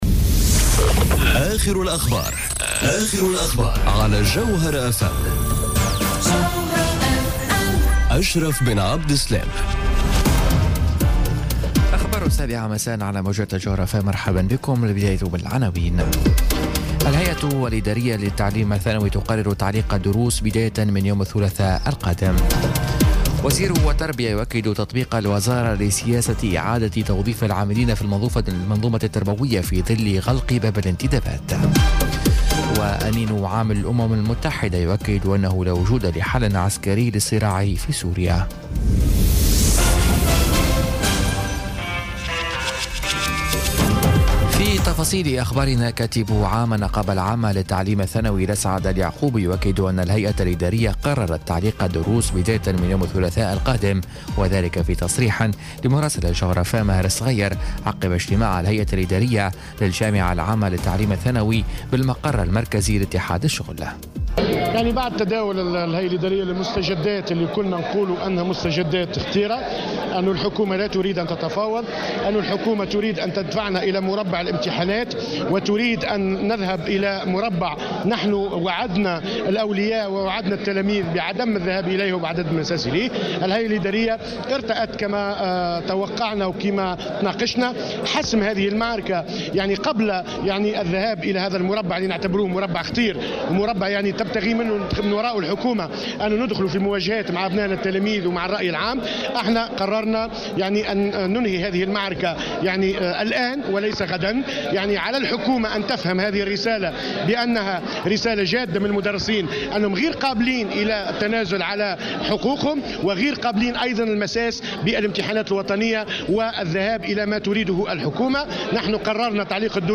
نشرة أخبار السابعة مساءً ليوم الجمعة 13 أفريل 2018